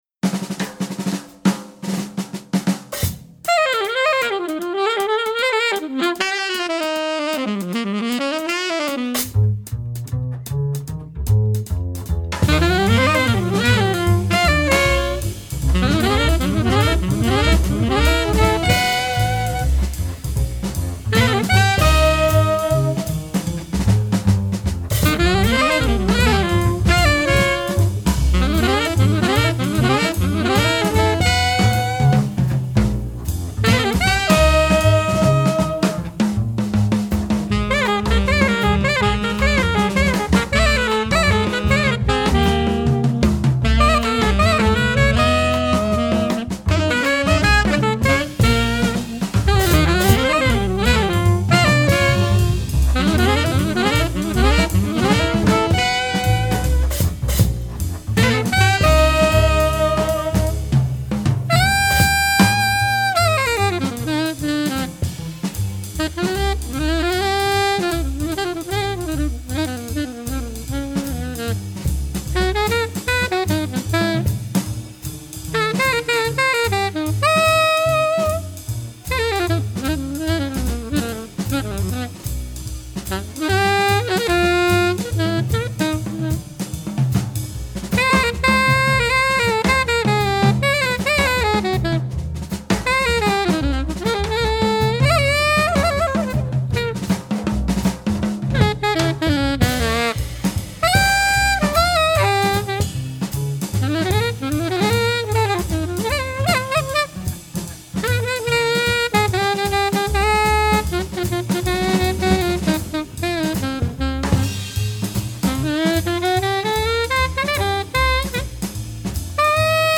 Jazz Saxophonist.